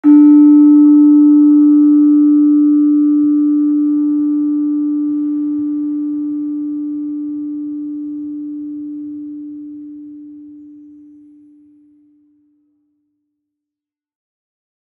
Gender-2-D3-f.wav